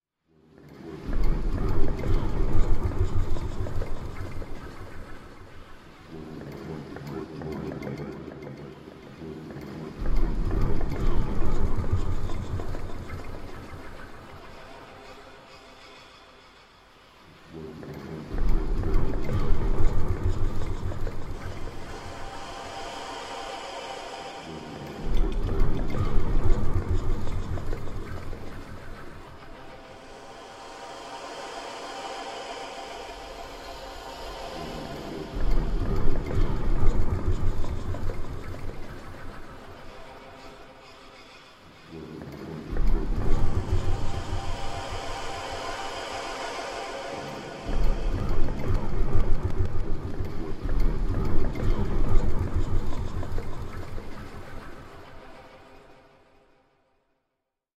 Звуки внутреннего голоса
Шепот мыслей в диалоге с собой